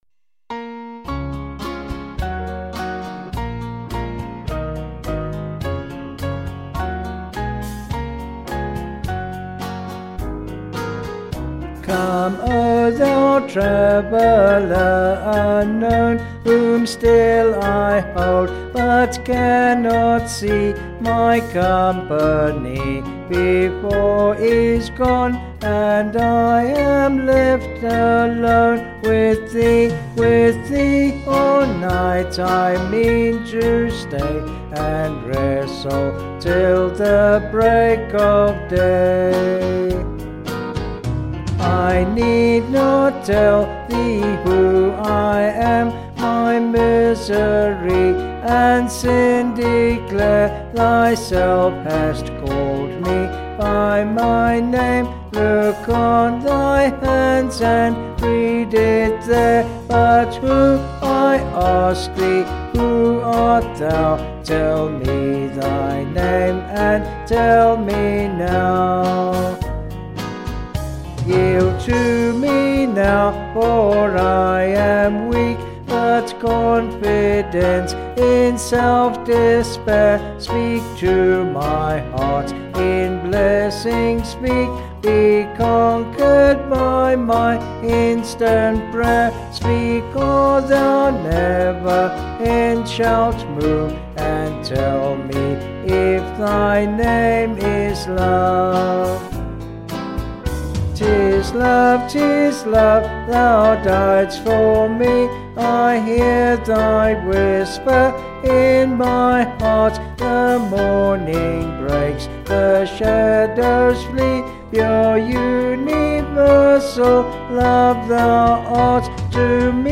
Vocals and Band   263.9kb Sung Lyrics